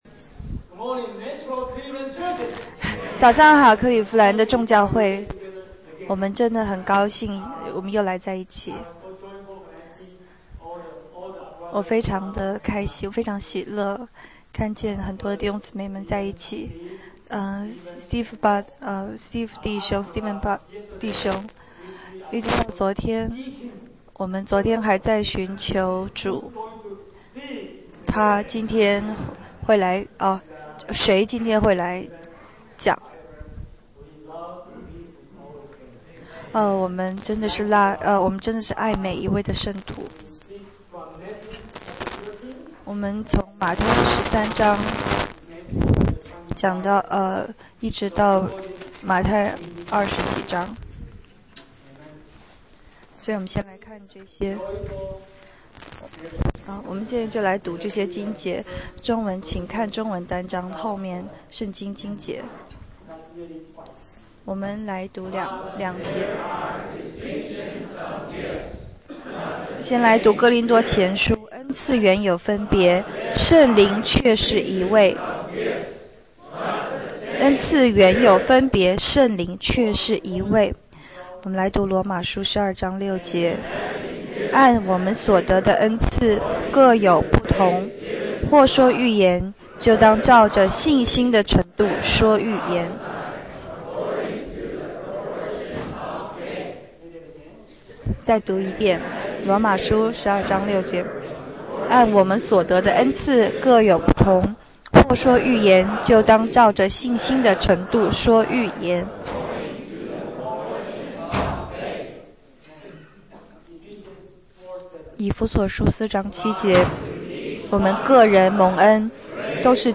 2010.1.3 主日–Jubilee
圣徒分享